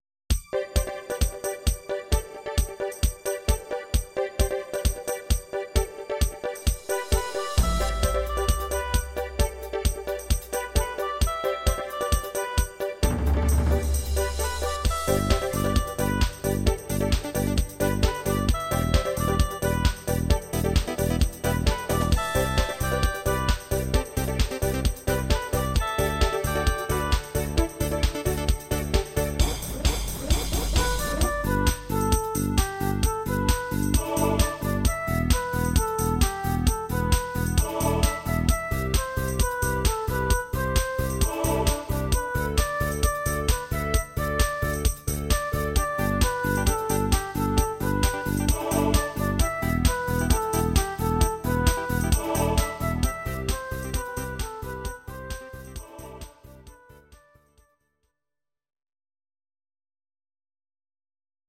Audio Recordings based on Midi-files
Pop, German, 2000s